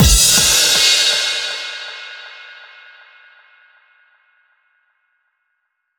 Big Drum Hit 20.wav